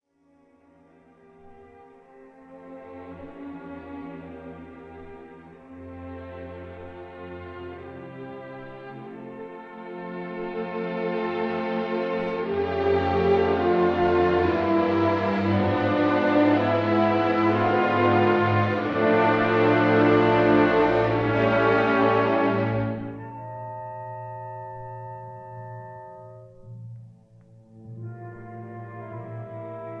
Andante and 5.